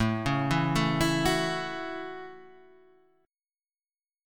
A6 Chord